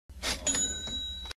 Play, download and share kaçing original sound button!!!!
para-sesi-efekti_MgcVzTT.mp3